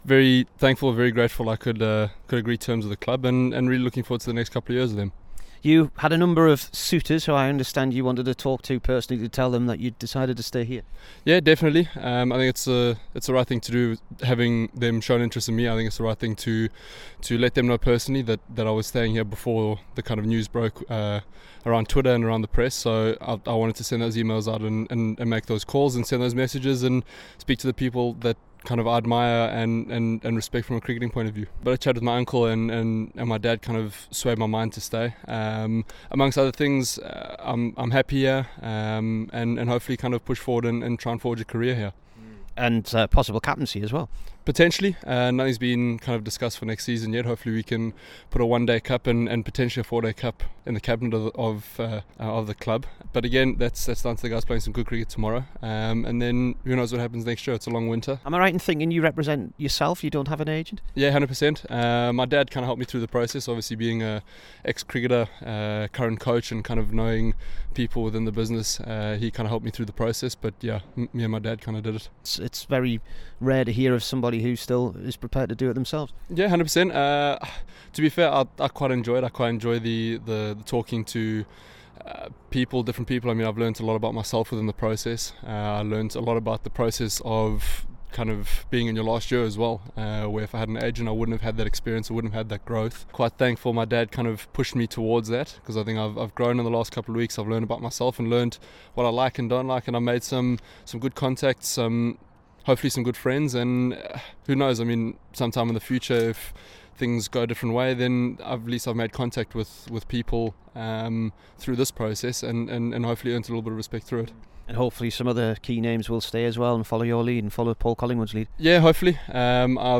KEATON JENNINGS INTERVIEW